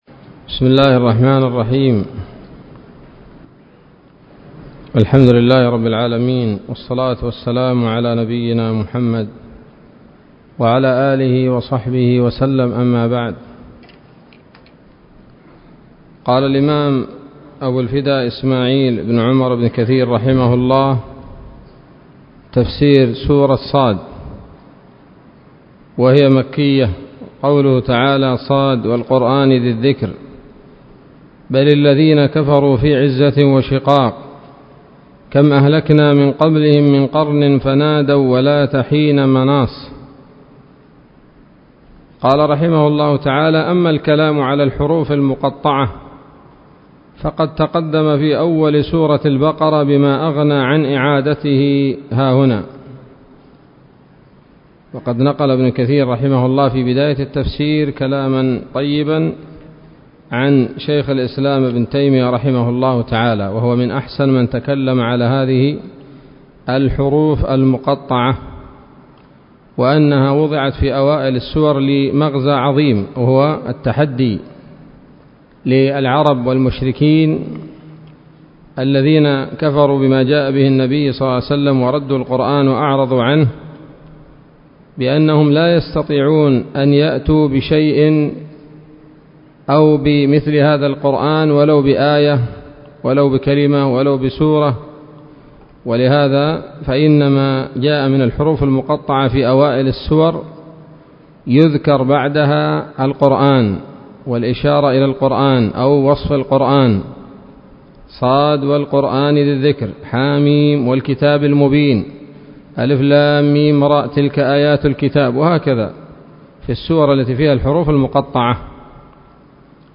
الدرس الأول من سورة ص من تفسير ابن كثير رحمه الله تعالى